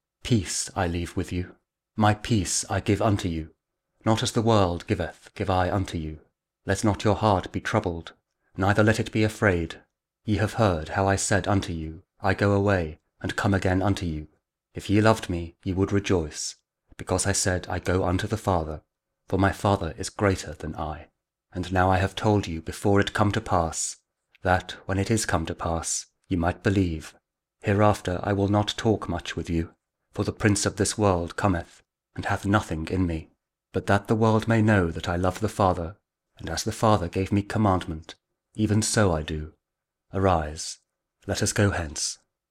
John 14: 27-31 | King James Audio Bible | Daily Verses